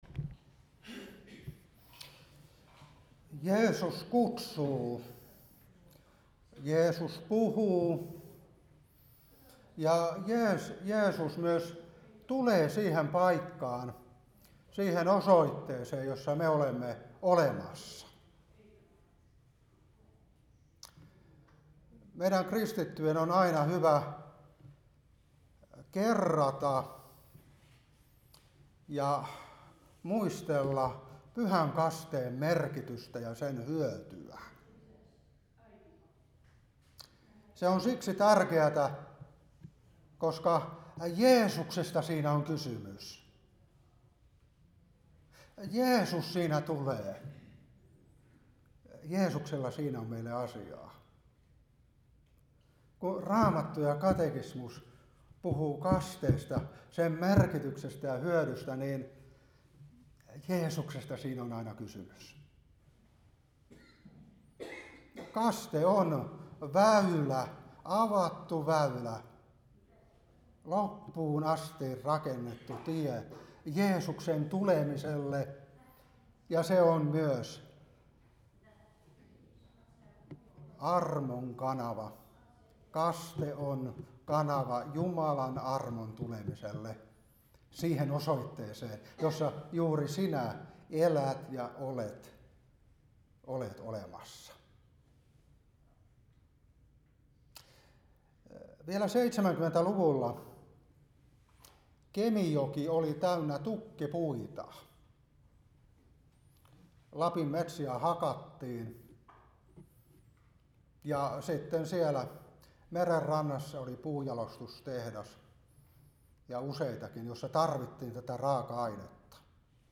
Seurapuhe 2024-8.